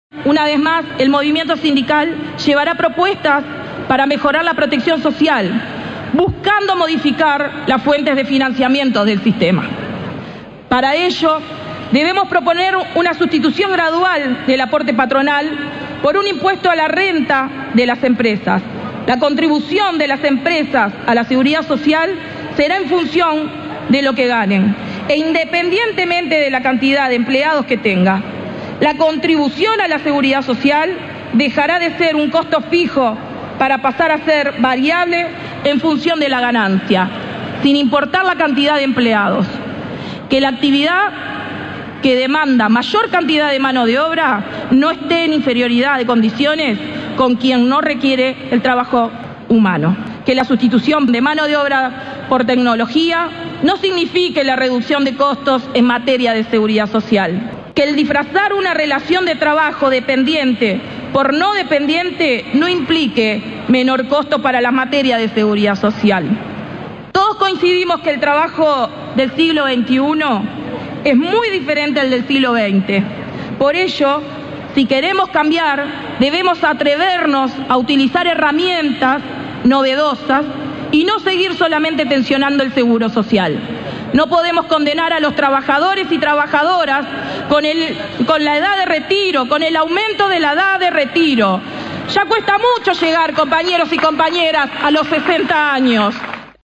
otra de las oradoras del acto